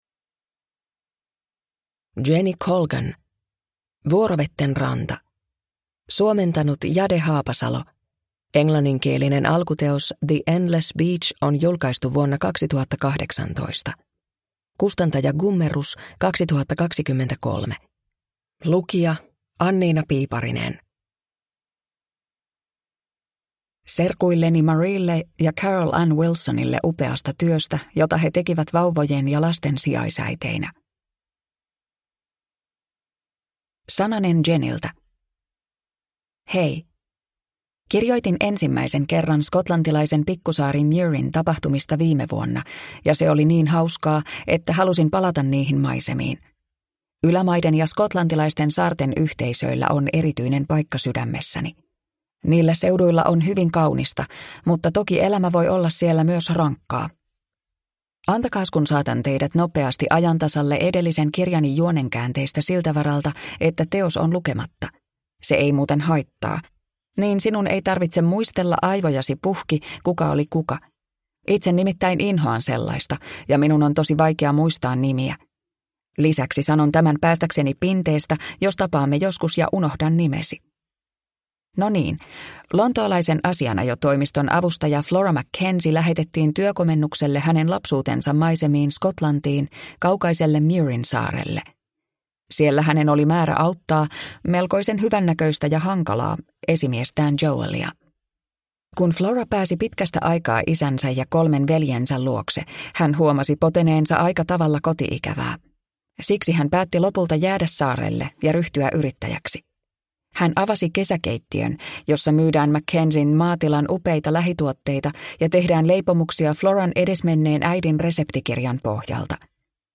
Vuorovetten ranta – Ljudbok – Laddas ner